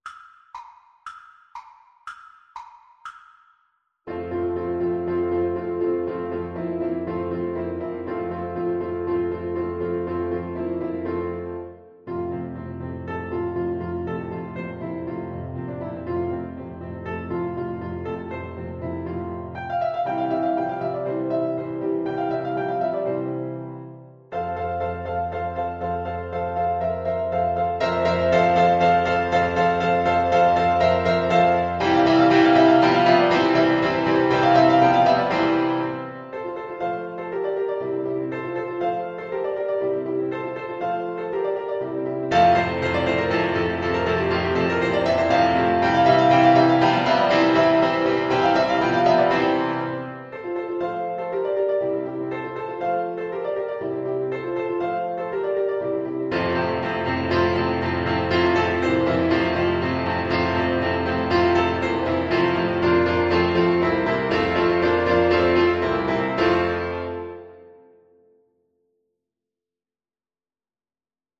Play (or use space bar on your keyboard) Pause Music Playalong - Piano Accompaniment Playalong Band Accompaniment not yet available transpose reset tempo print settings full screen
F major (Sounding Pitch) G major (Clarinet in Bb) (View more F major Music for Clarinet )
2/4 (View more 2/4 Music)
Classical (View more Classical Clarinet Music)